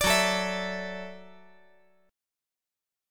F#m7#5 Chord
Listen to F#m7#5 strummed